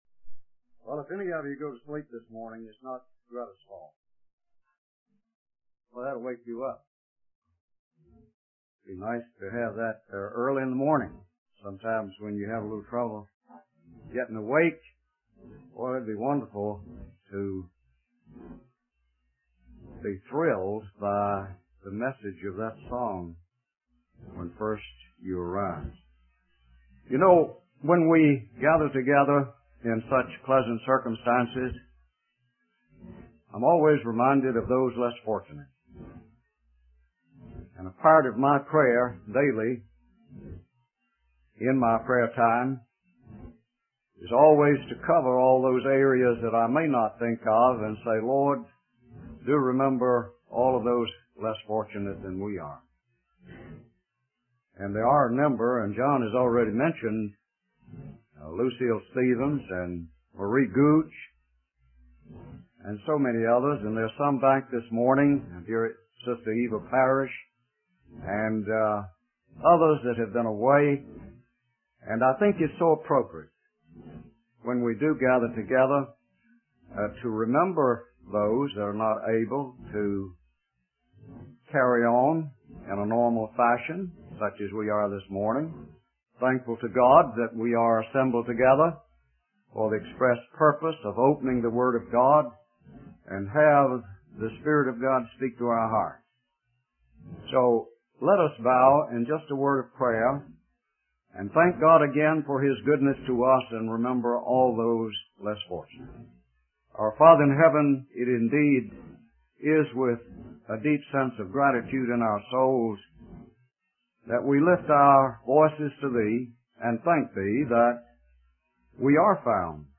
In this sermon, the speaker emphasizes the importance of Christians being used by God to witness to others about salvation.